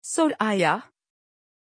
Pronunciation of Sorayah
pronunciation-sorayah-tr.mp3